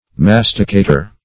Masticater \Mas"ti*ca`ter\, n. One who masticates.